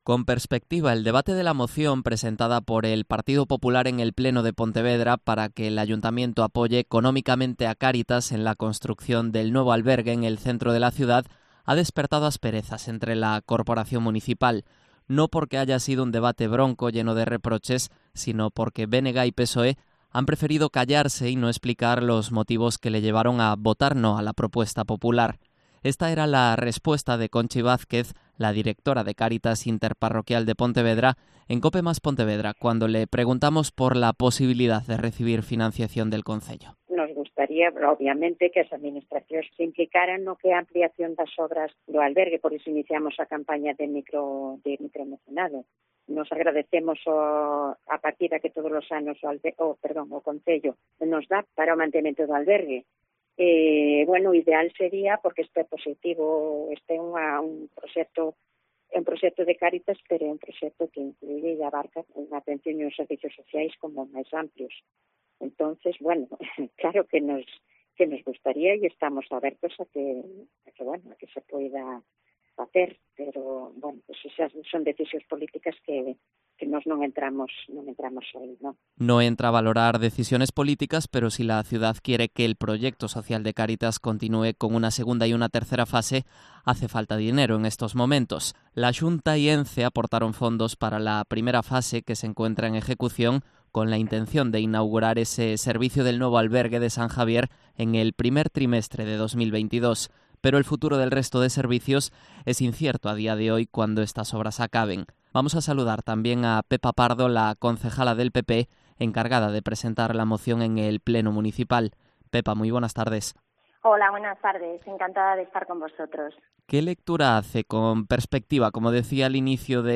Entrevista a Pepa Pardo, concejala del PP de Pontevedra